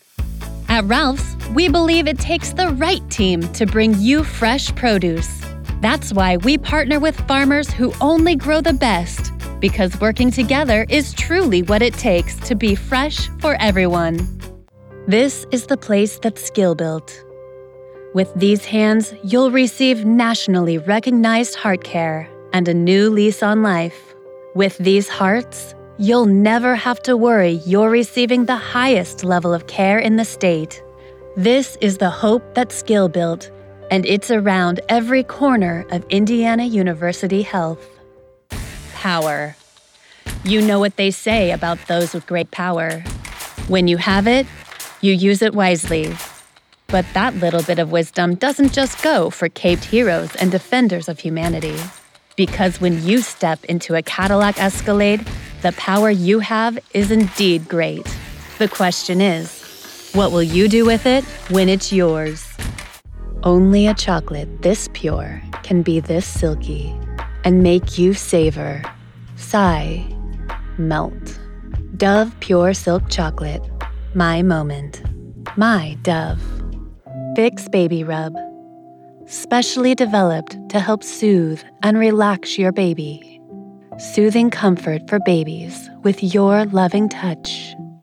Tarrawarra Winery Voice Over Commercial Actor + Voice Over Jobs
English (British) Adult (30-50)
He has an assured vocal delivery, with a clear, professional edge.